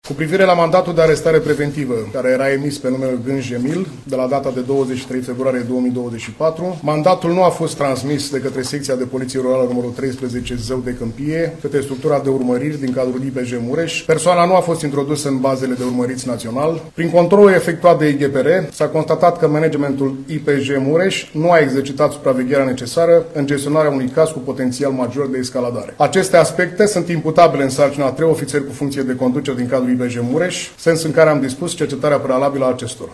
Șeful Poliției Române, Benone Matei: „Persoana nu a fost introdusă în bazele de urmăriți național”